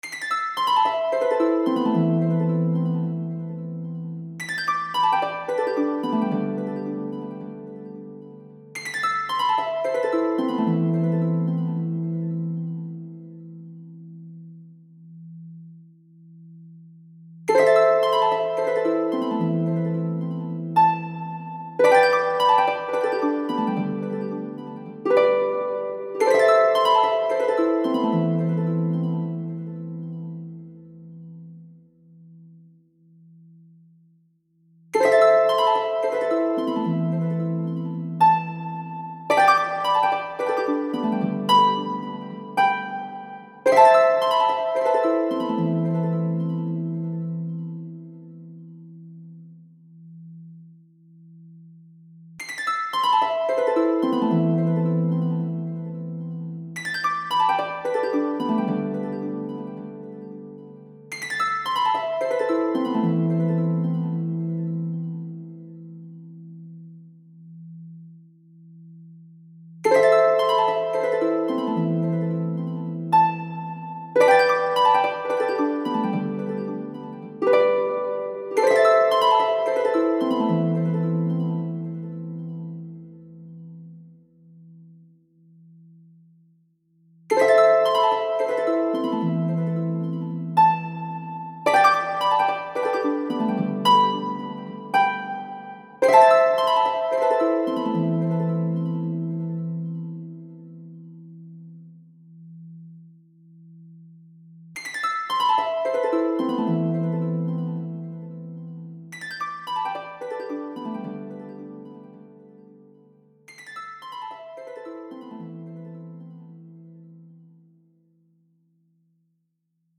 流れ落ちるようなハープの曲です